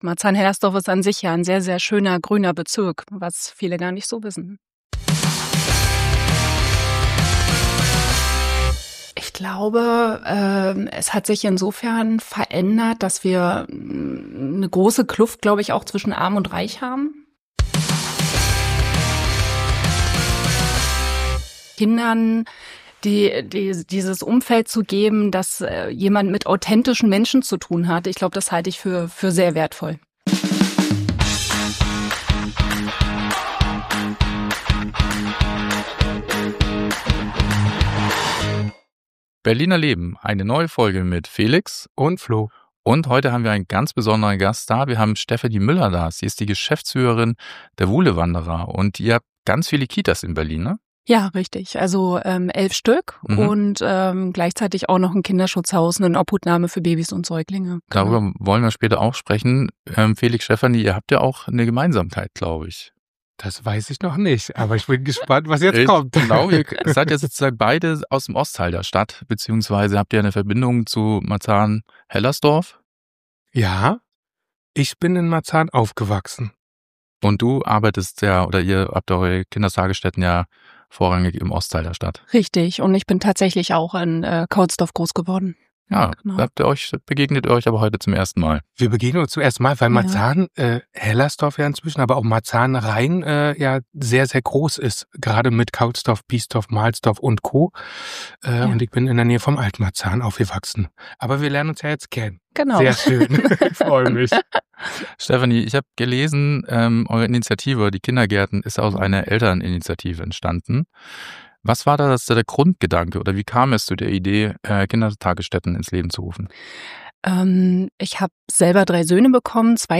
Ein tiefes, ehrliches, berührendes Gespräch über Kindheit, Verantwortung, Stadtentwicklung und Berlin zwischen Lärm, Grünflächen, sozialer Realität und großem Herzen.